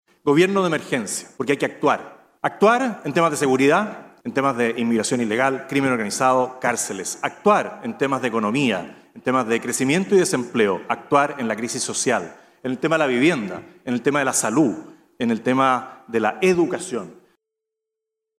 Sin mayores polémicas se desarrolló el panel presidencial en el Encuentro Nacional de la Empresa (Enade) 2025, en el que participaron siete de los ocho candidatos que buscan llegar a La Moneda.
Palabras finales de los candidatos
216-cu-presidencial-jose-antonio-kast.mp3